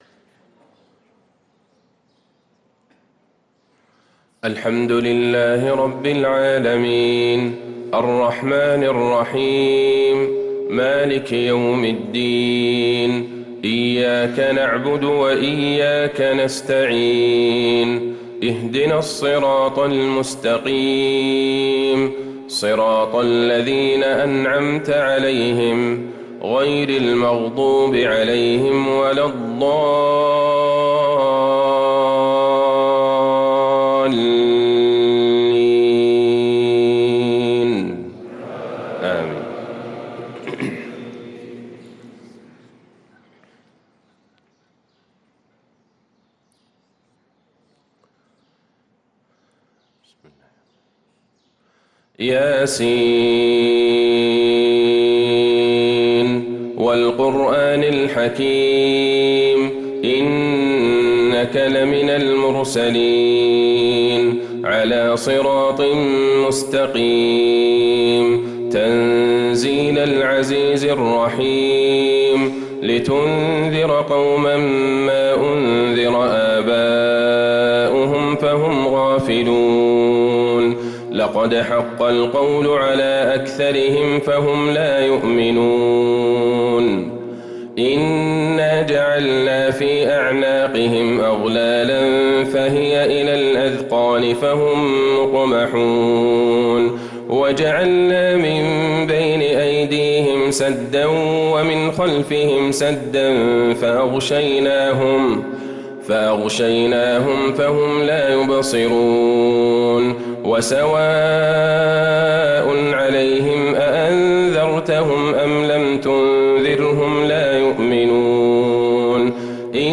صلاة الفجر للقارئ عبدالله البعيجان 10 رجب 1445 هـ
تِلَاوَات الْحَرَمَيْن .